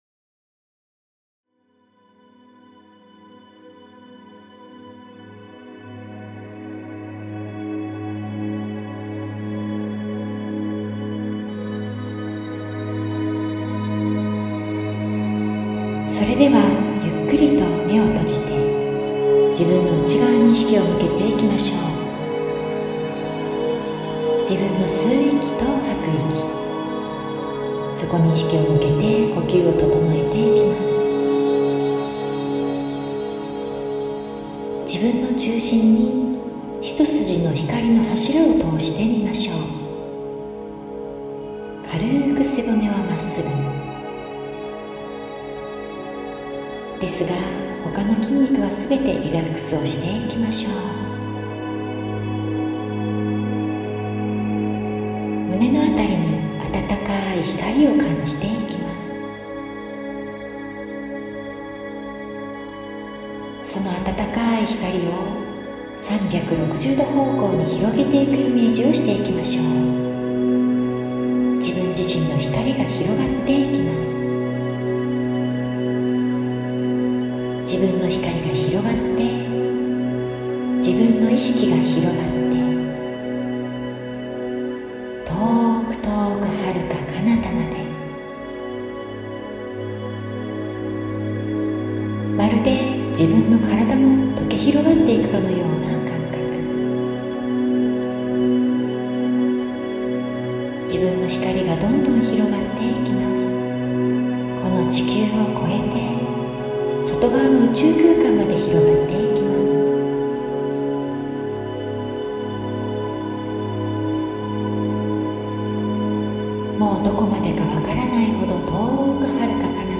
LINE特典＿誘導瞑想